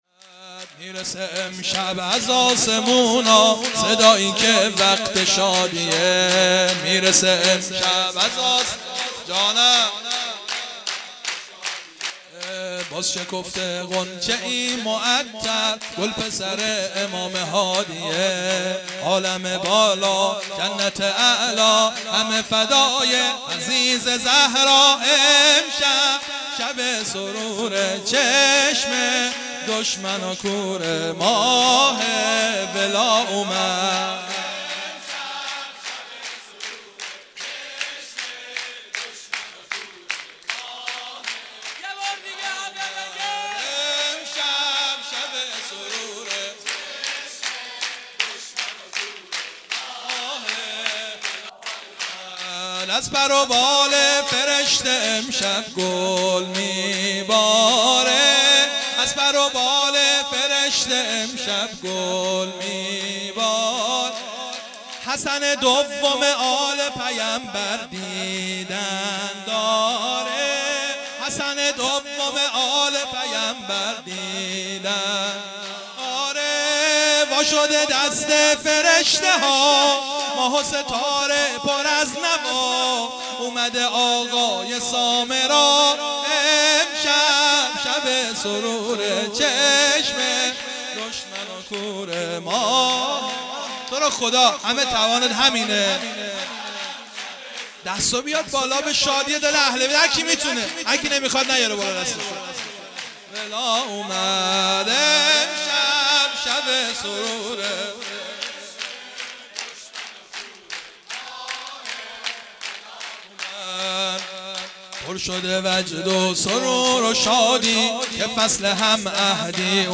شور - می رسه امشب از آسمونا صدایی که وقت شادیه
مراسم 18 دی ماه 1395 - میلاد امام حسن عسکری (ع)
جشن سرود